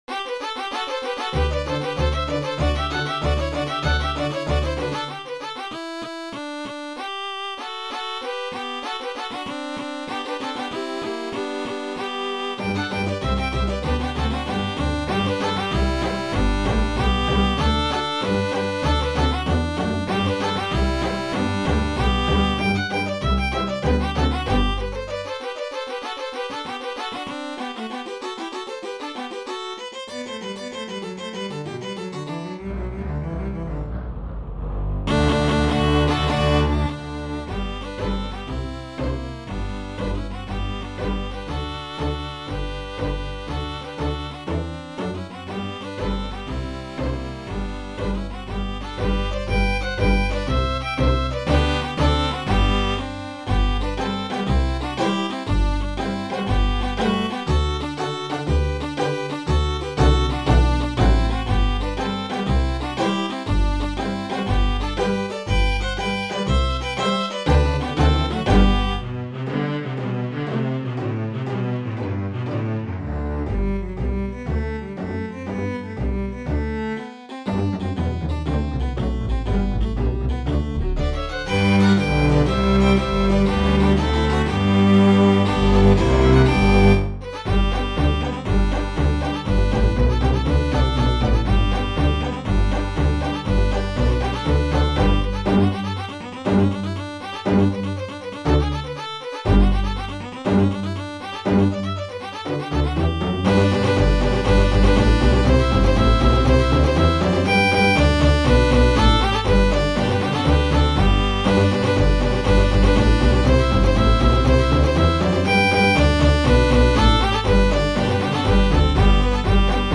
This is my arrangement of two old fiddle tunes: THE ARKANSAS TRAVELER and TURKEY IN THE STRAW.
BLUEGRASS MUSIC; COUNTRY MUSIC